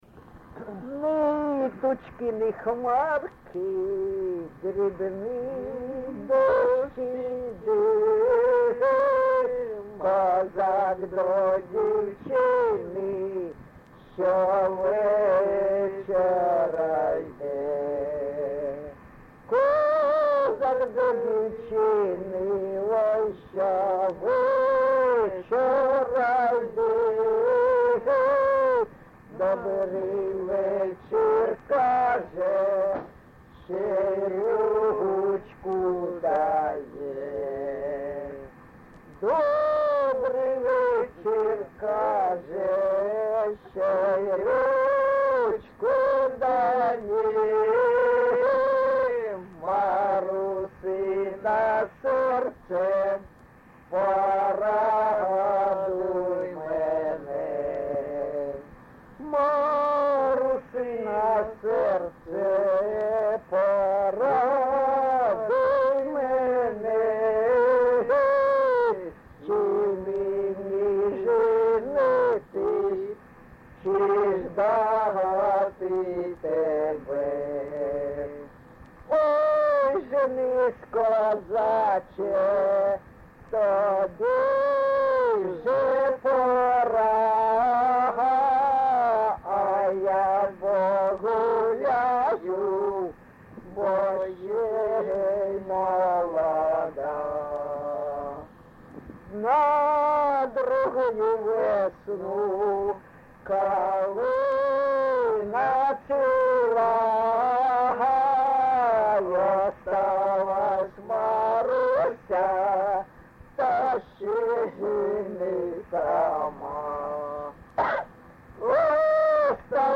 ЖанрПісні з особистого та родинного життя
Місце записум. Дебальцеве, Горлівський район, Донецька обл., Україна, Слобожанщина